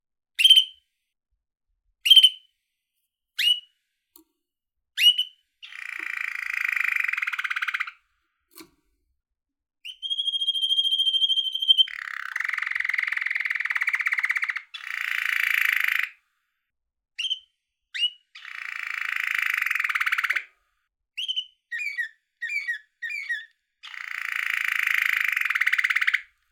Canary Trill Yellow.ogg
little yellow canary playing, trilling and chirping in his birdgage.
canary_trill_yellow_2w6.mp3